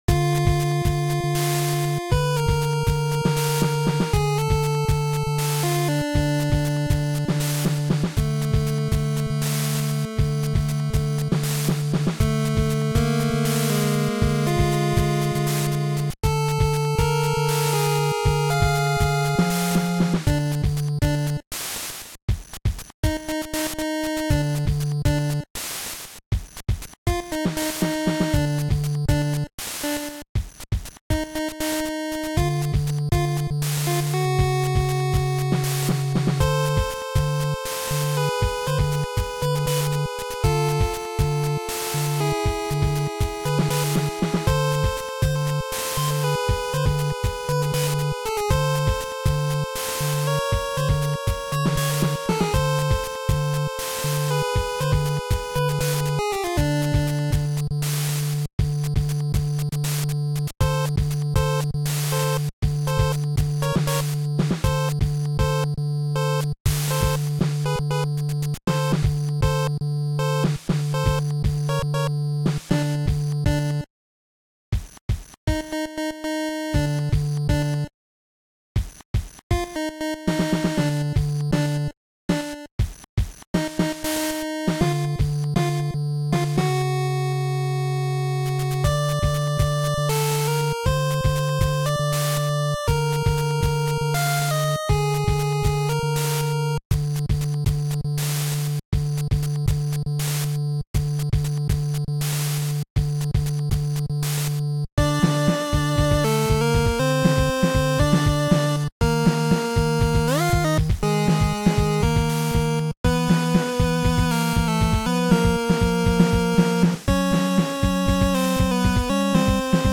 Made using Dn-Famitracker.